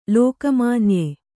♪ lōka mānye